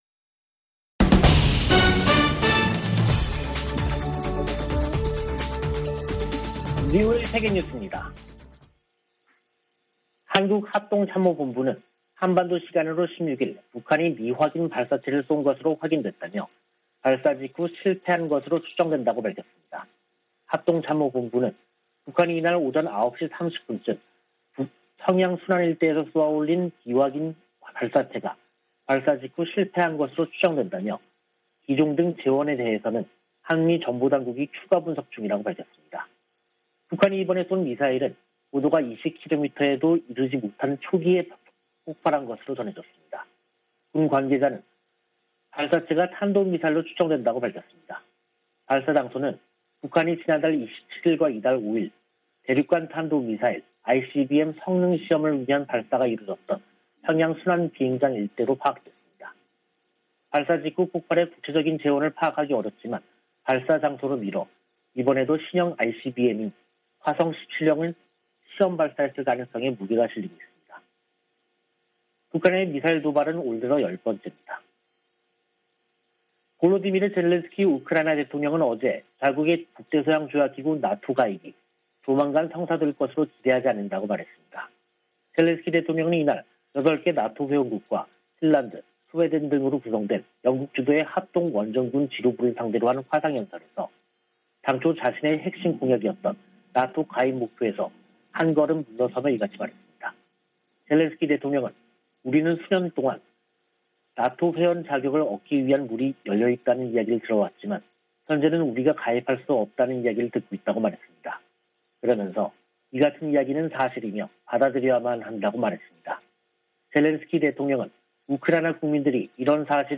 VOA 한국어 간판 뉴스 프로그램 '뉴스 투데이', 2022년 3월 16일 3부 방송입니다. 한국 합동참모본부는 북한이 16일 평양 순안 일대에서 미확인 발사체를 발사했으나 실패한 것으로 추정된다고 밝혔습니다. 미 국무부는 북한의 탄도미사일 시험발사를 규탄하고, 대화에 나서라고 촉구했습니다. 유럽연합(EU)은 북한의 최근 탄도미사일 발사와 관련해 추가 독자 제재 부과를 검토할 수 있다는 입장을 밝혔습니다.